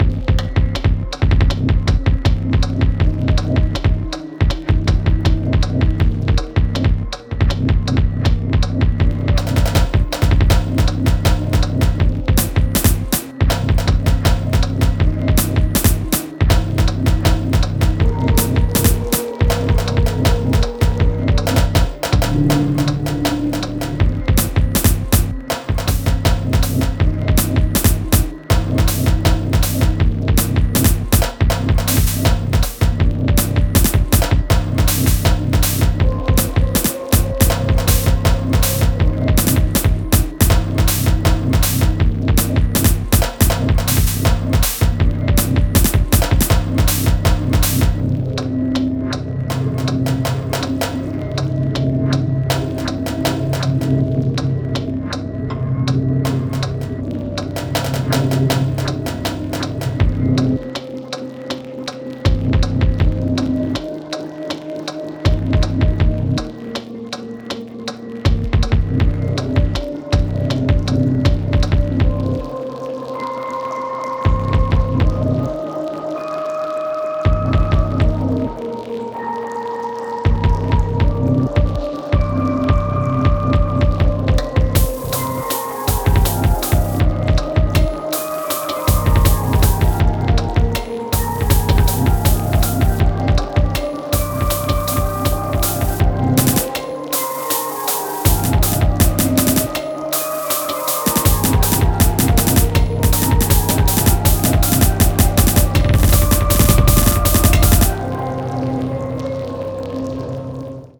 Hey! This is Roland sh-101 + 303 going through a satt sam 82 desk → studer 900 channel strip → dbx 166 (parallell processed via boss ls-2).
Fx is boss rv-5 and eventide dsp-4000.